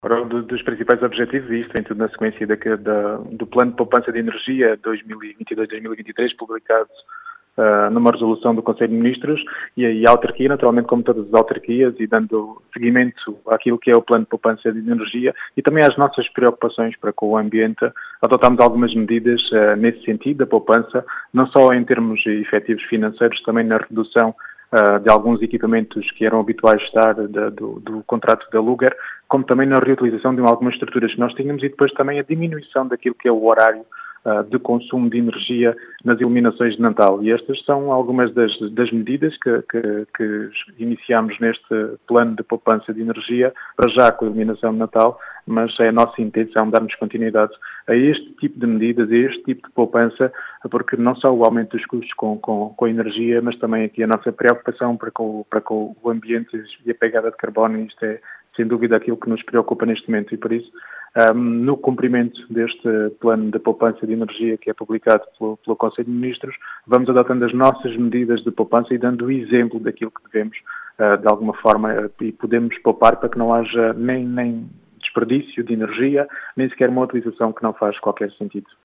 As explicações são de Rui Raposo, presidente da Câmara Municipal de Vidigueira, que pretende “dar o exemplo” na poupança de energia.